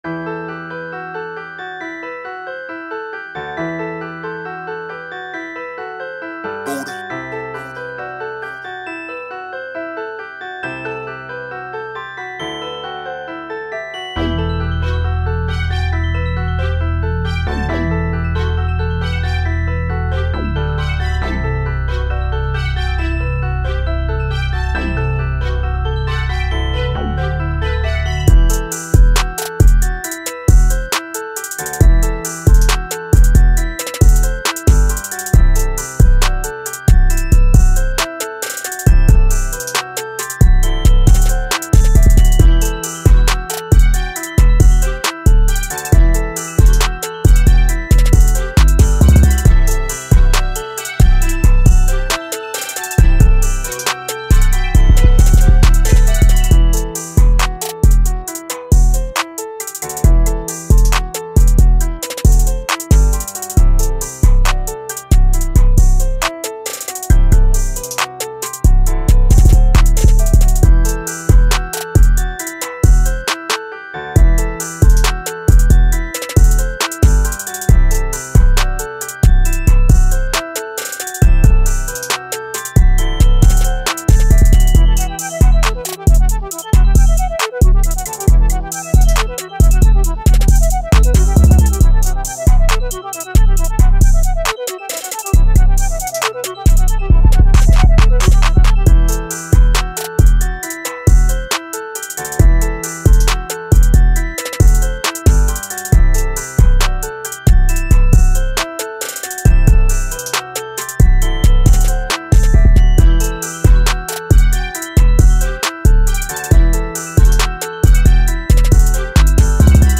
Hip Hop, Trap Beat
This infectious track, rich in rhythm and energy